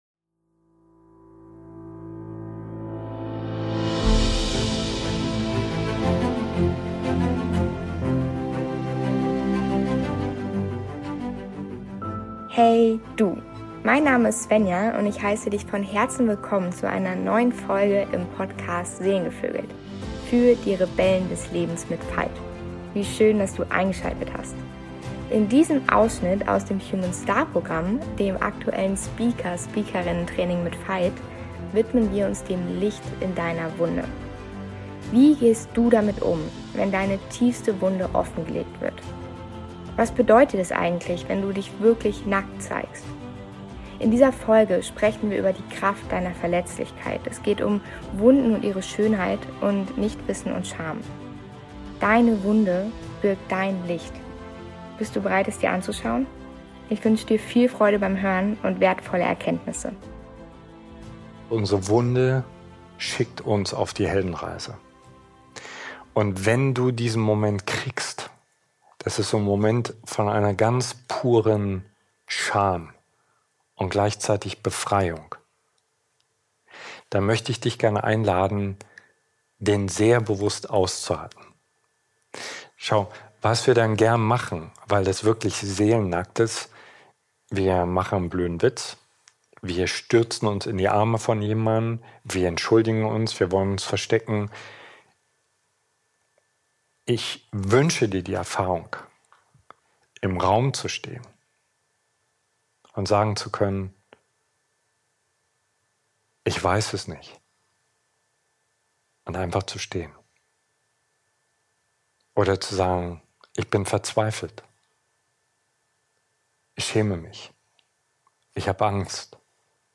Das Licht in deiner Wunde | Von der Kraft deiner Verletzlichkeit | Talk | Folge 188
In diesem ganz besonderen Ausschnitt aus unserer Speaker:innen Ausbildung, dem humanSTAR Programm, widmen wir uns dem Licht in deiner Wunde.